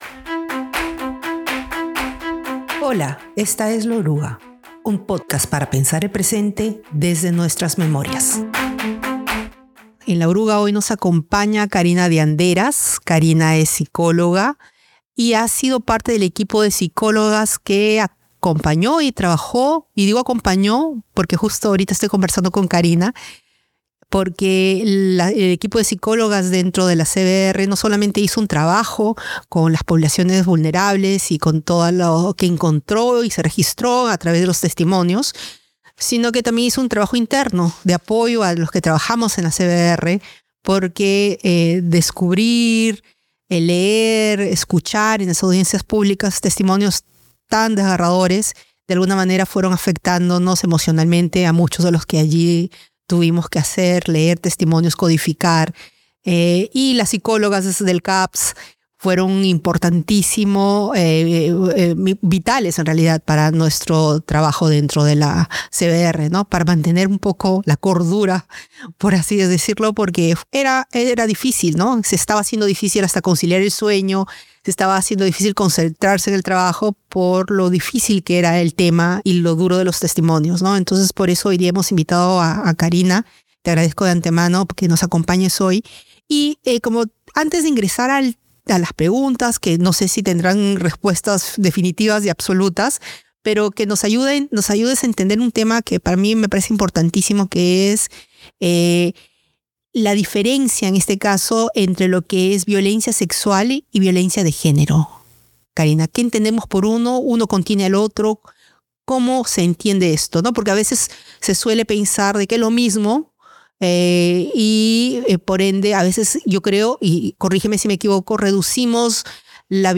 Conversando